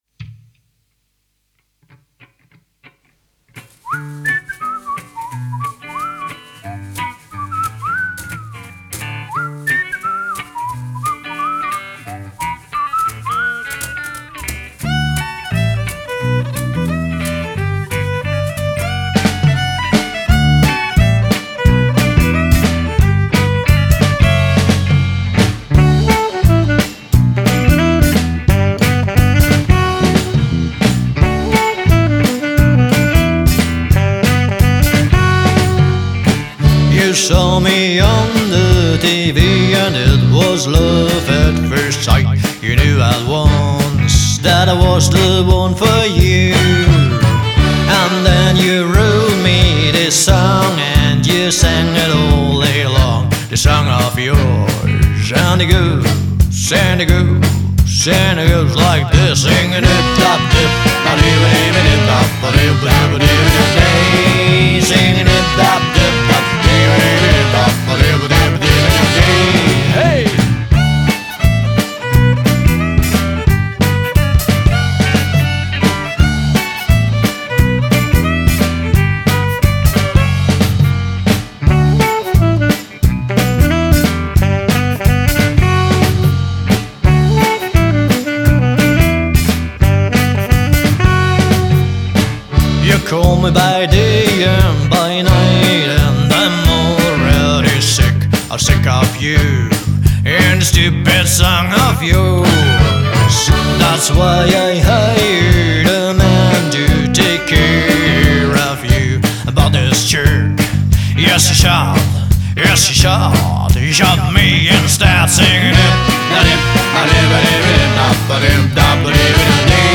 Genre: Folk-Rock, Gypsy-Punk